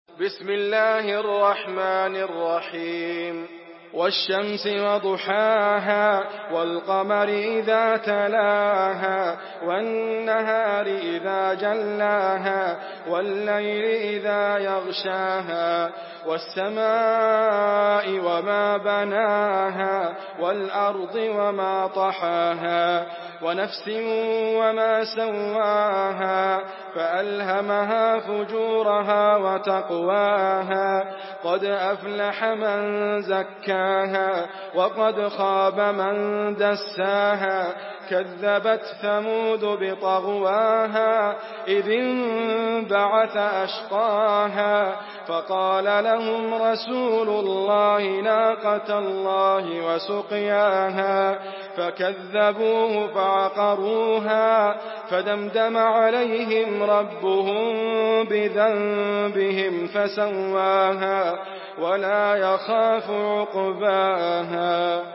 تحميل سورة الشمس بصوت إدريس أبكر
مرتل